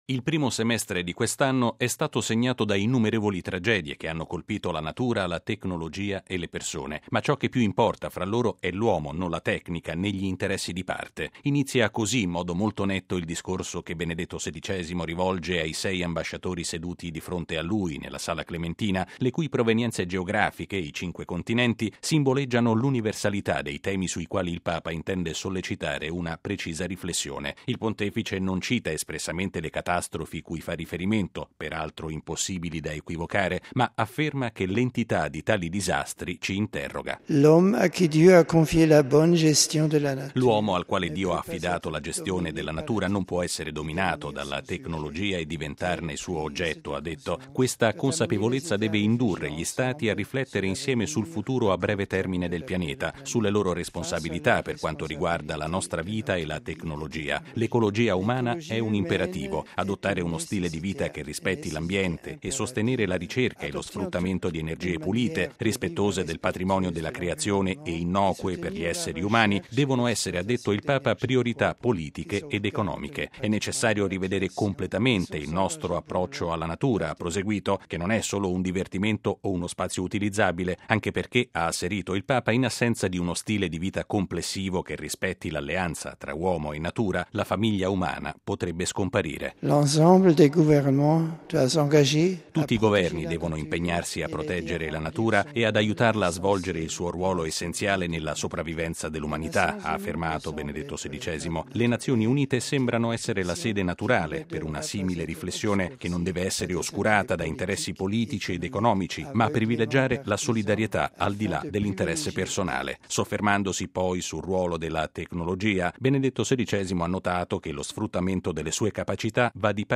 Nel discorso collettivo, pronunciato in lingua francese, il Papa ha ribadito che la riflessione sulla tutela dell’ambiente non deve essere condizionata da fini politici o economici.